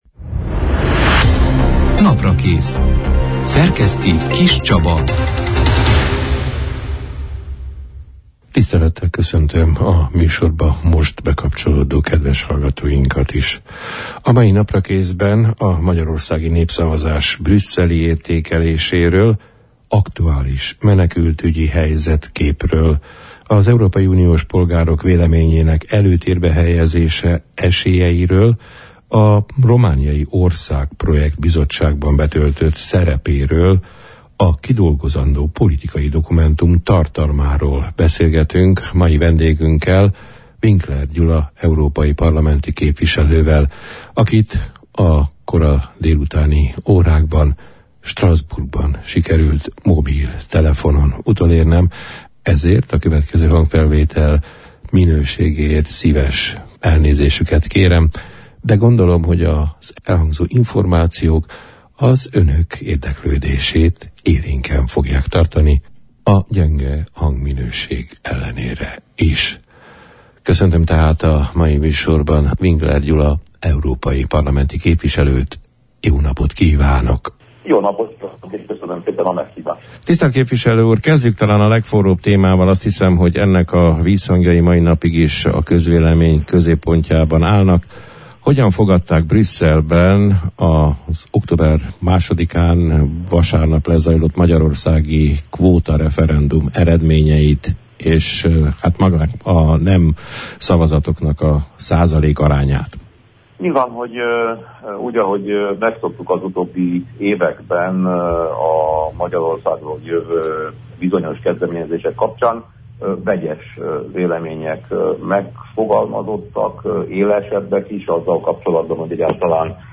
A magyarországi népszavazás eredményeinek brüsszeli elbírálásáról, aktuális menekültügyi kérdésekről, az EU-s polgárok véleményének hangsúlyosabb figyelembe vételéről, az országprojekt – bizottságban betöltött szerepéről, a kidolgozandó politikai dokumentum tartalmáról beszélgettünk az október 5 -én, szerdán elhangzott Naprakész műsorban Winkler Gyula EP-i képviselővel.